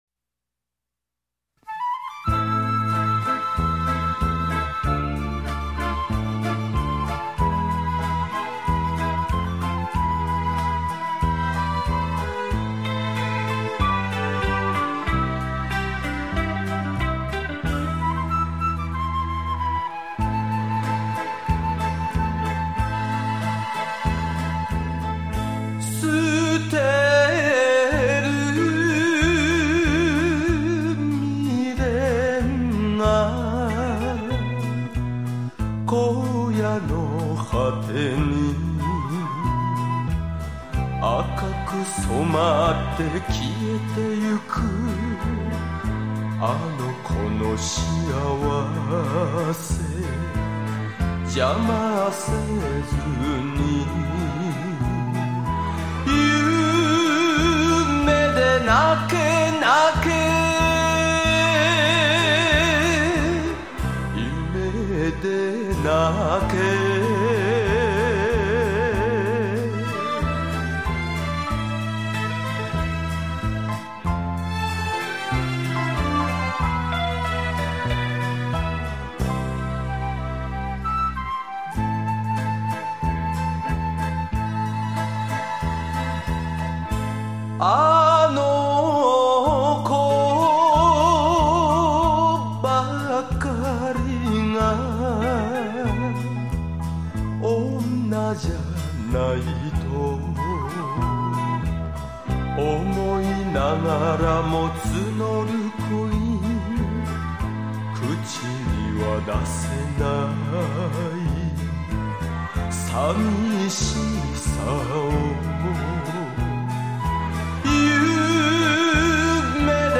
怀念演歌
收录日本演歌精选 曲曲动听 朗朗上口